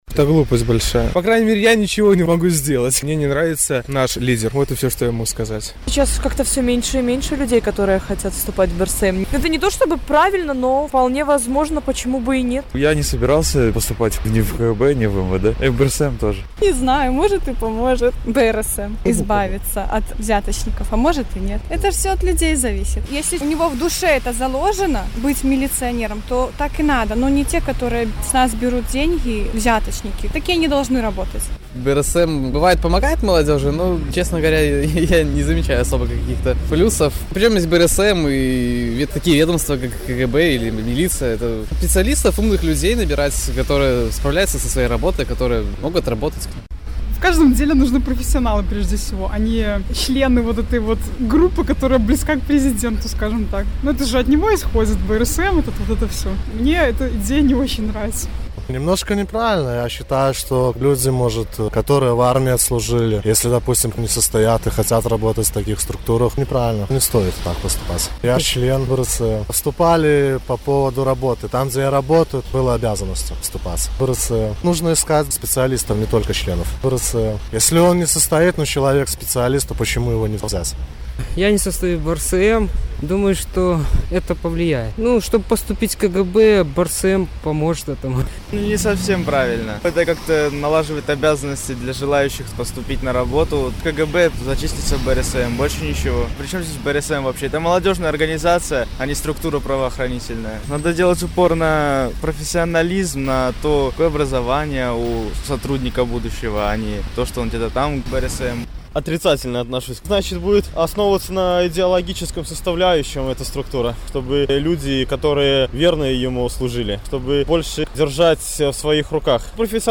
На гэтае пытаньне адказваюць жыхары Гомеля